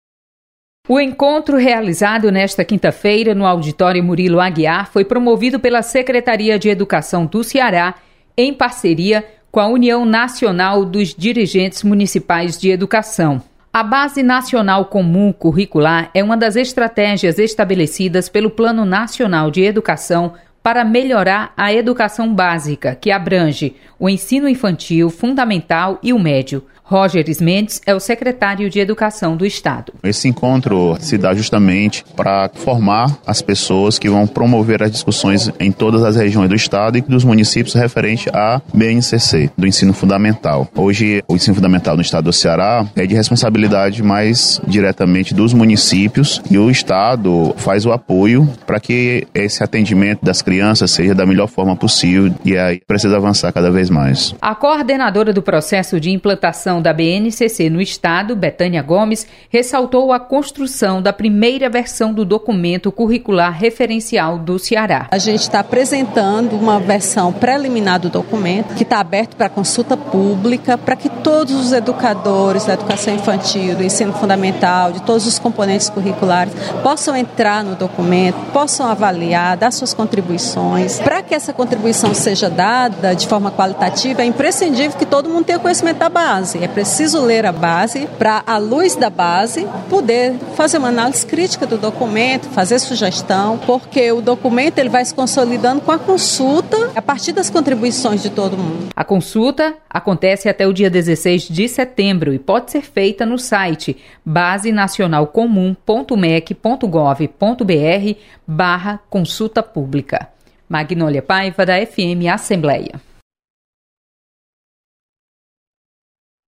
Assembleia Legislativa sedia discussão sobre a Base nacional Curricular Comum. Repórter